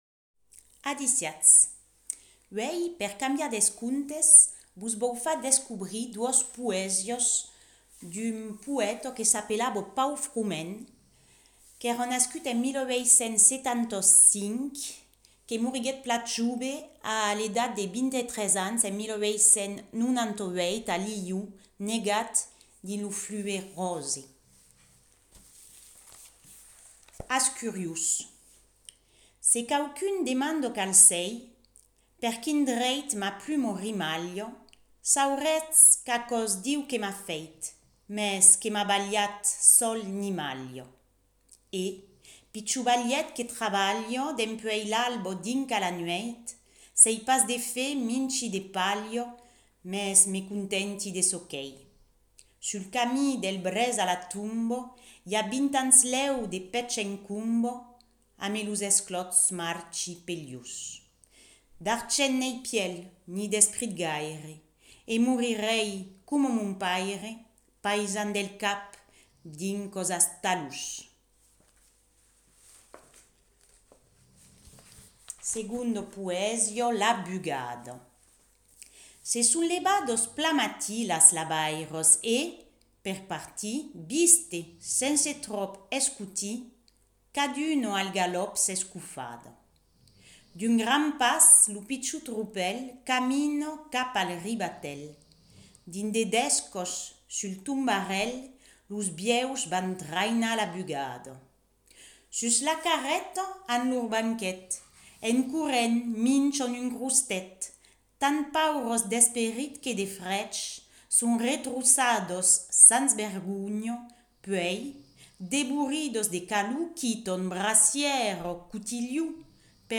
Per cambiar dels contes, ai decidit d'enregistrar 2 poésias de Paul Froment: Als curiós e La bugada (A travèrs regas, Lo libre de Paul de Paul Froment, Escòla Occitana d'Estiu, 1986).